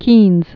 (kēnz)